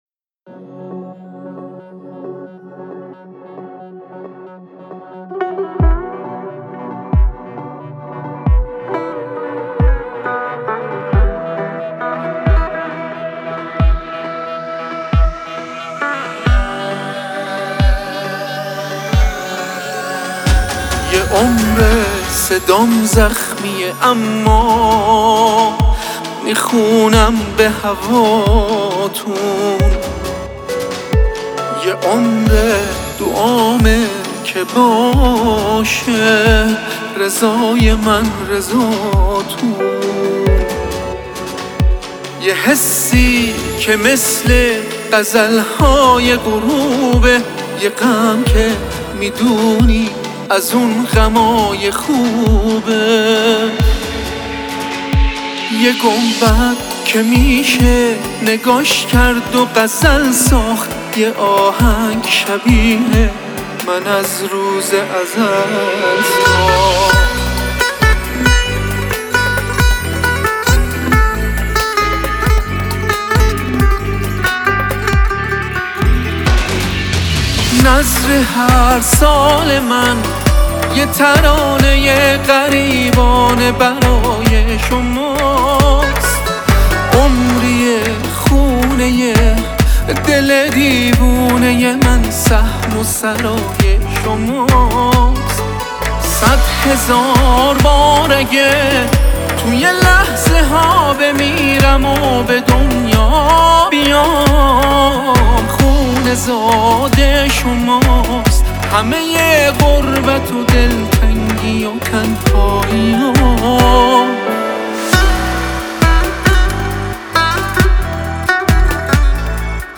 خواننده موسیقی پاپ